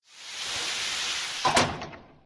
DoorOpen2.ogg